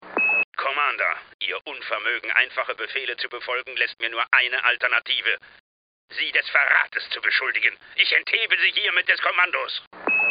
Jack Nicholson appears as the bitter narrator who mysteriously prepares the player for each of the missions, and Clint Eastwood is the American general who always has a frustrating comment to offer whenever the player disregards his superior's orders.
General Collins: